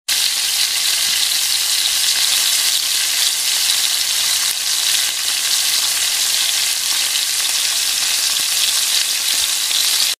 deepfry.ogg